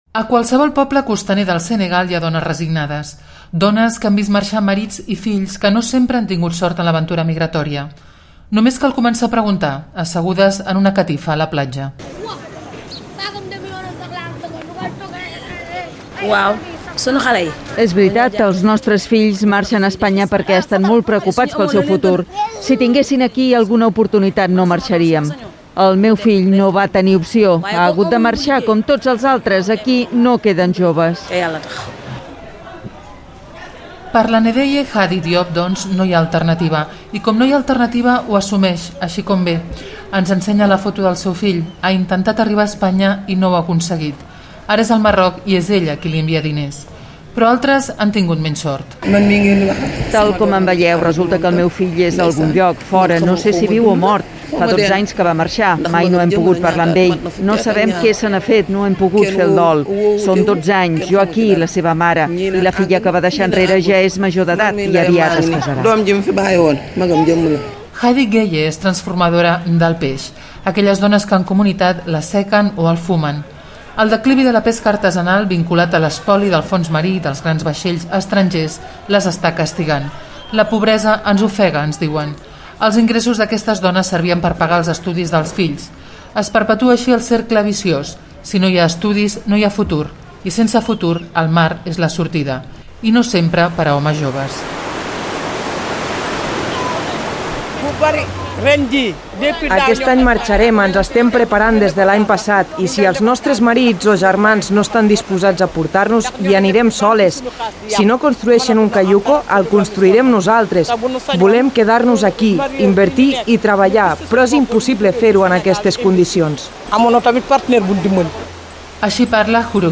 Mares senegaleses opinen sobre la marxa de marits i fills per intentar treballar a Espanya i expliquen les dificultats que tenen per guanyar-se la vida venen peix, degut a la pesca il·legal
Informatiu